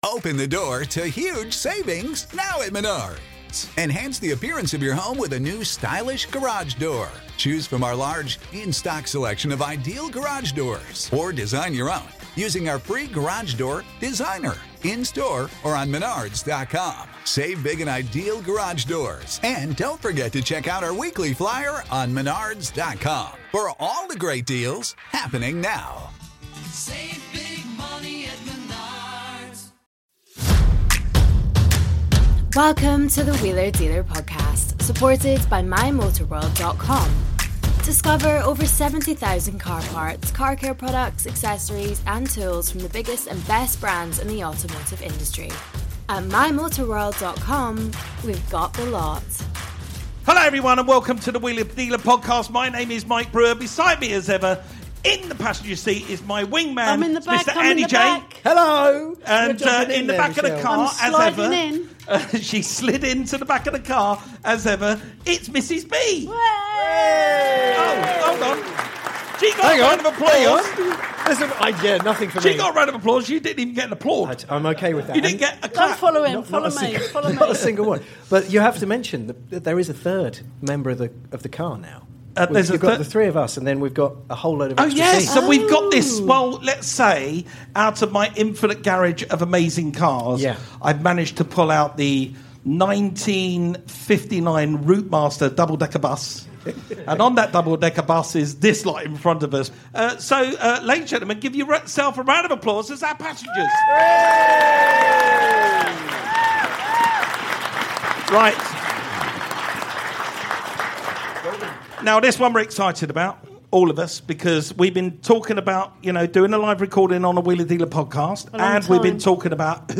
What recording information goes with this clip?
another live episode at The Motorist in Leeds